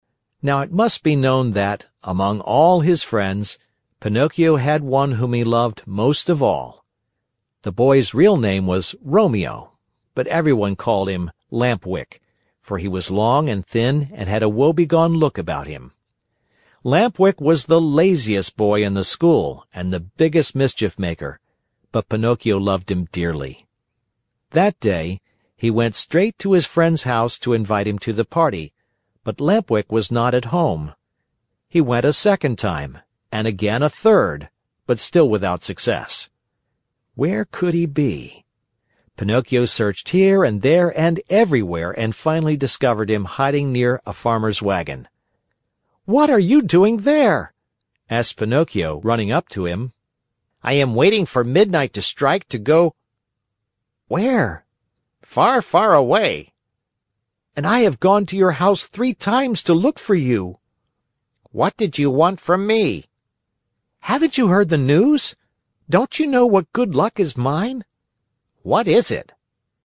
在线英语听力室木偶奇遇记 第104期:匹诺曹与小灯芯(2)的听力文件下载,《木偶奇遇记》是双语童话故事的有声读物，包含中英字幕以及英语听力MP3,是听故事学英语的极好素材。